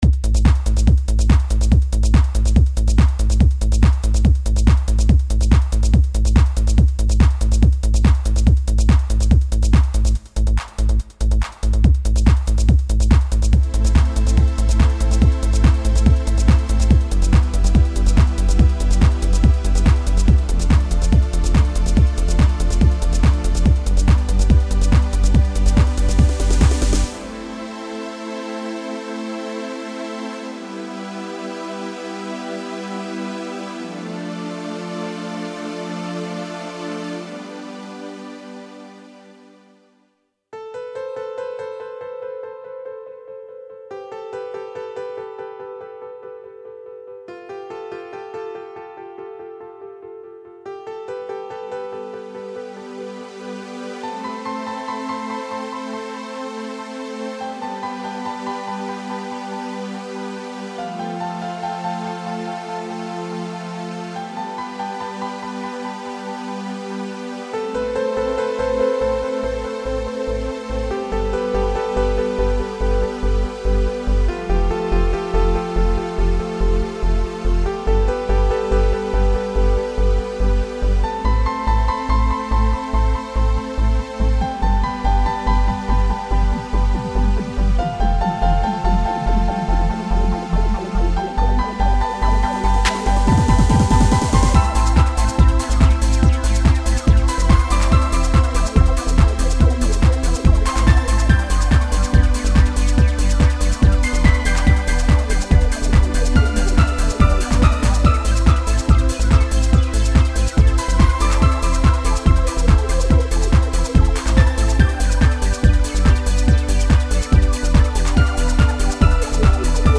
/Dutch Trance   MIDI(49 KB)
Tranceっぽくしたつもり。